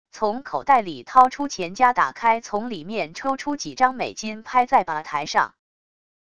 从口袋里掏出钱夹打开从里面抽出几张美金拍在吧台上wav音频